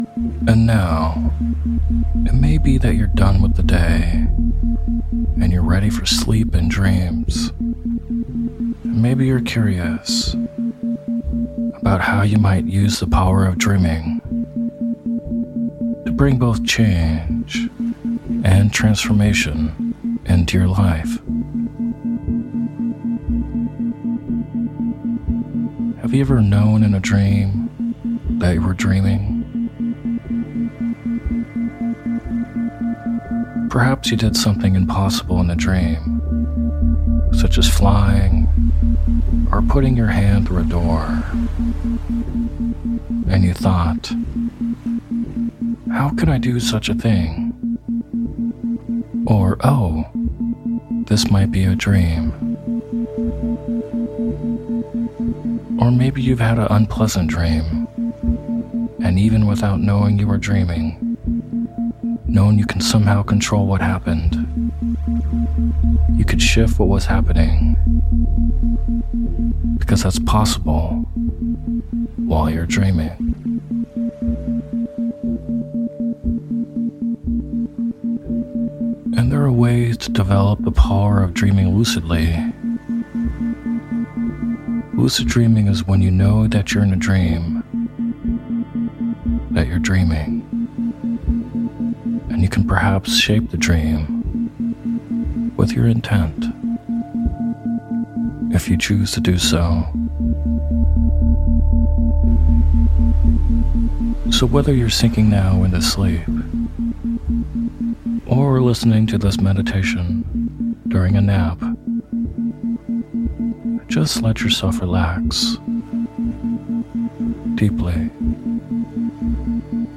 It includes tips and techniques for achieving lucidity. This meditation also includes shamanic drumming.